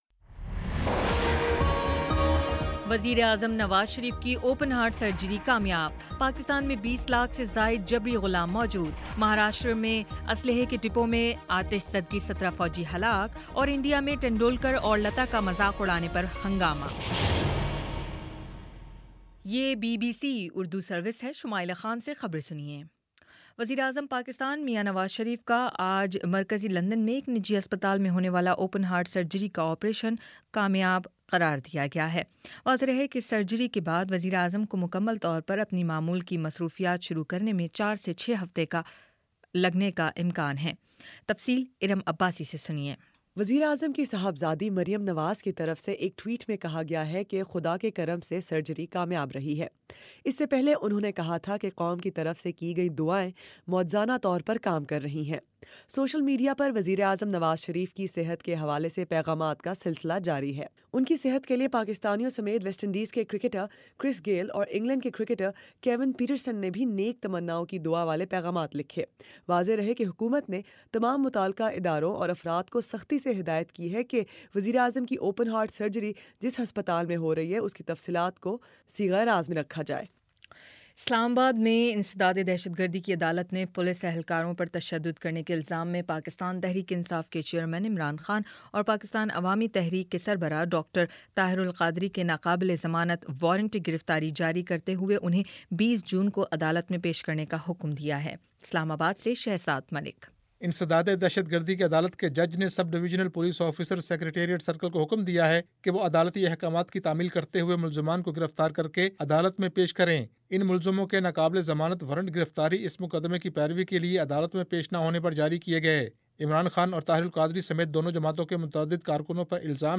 مئی 31 : شام چھ بجے کا نیوز بُلیٹن